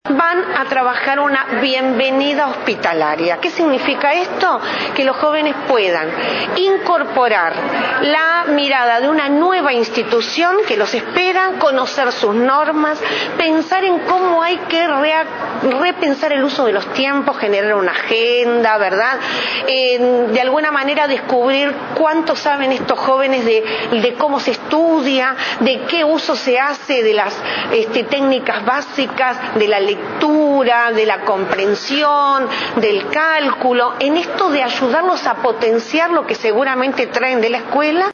Celsa Puente, directora general de secundaria, dijo a la secretaría de comunicación de presidencia que preocupa la deserción de estudiantes en el tránsito de primaria a secundaria por lo que se va a aplicar un programa con docentes y estudiantes de secundaria, que acompañen a los niños que ingresan al liceo.